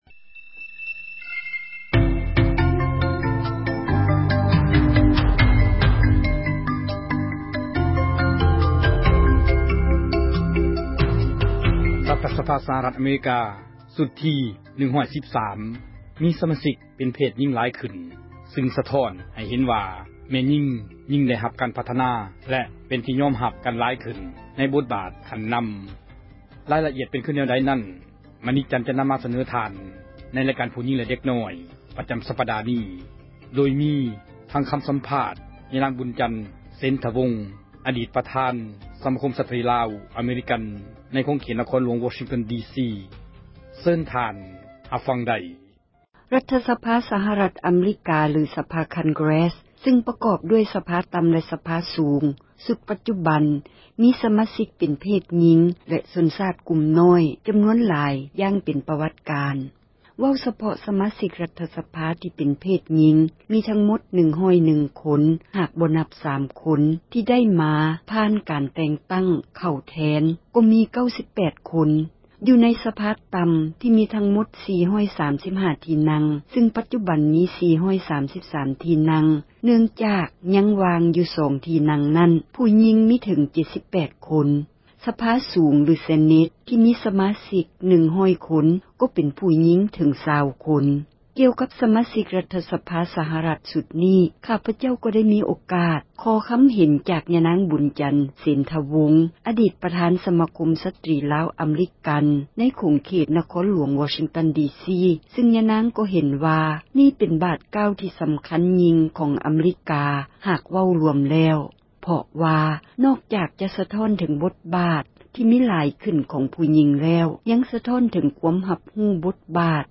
ໂດຍມີຄໍາ ສັມພາດ